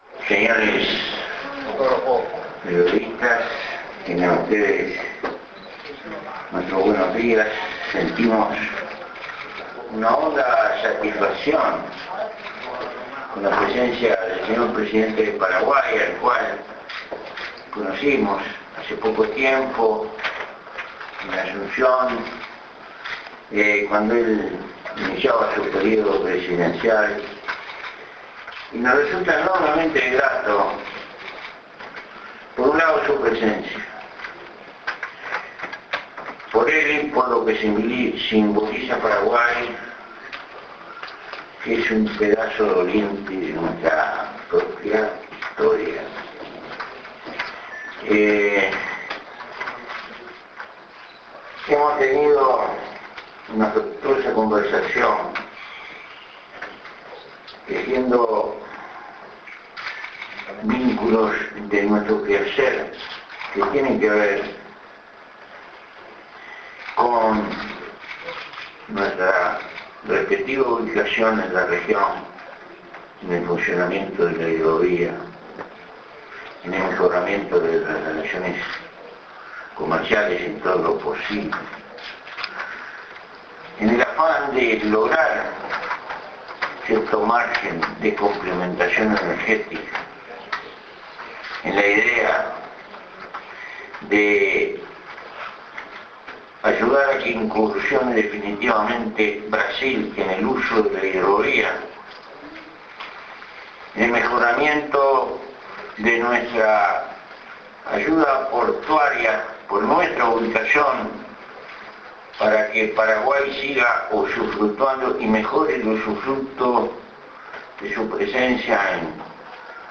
Confer�ncia de Imprensa de Mujica e Cartes